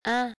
"in" as in "Hein??"
Oddly enough, you can pronounce it when you exaggerate the pronunciation of “can’t” or “and”.